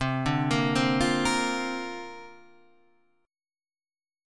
Listen to C7sus2sus4 strummed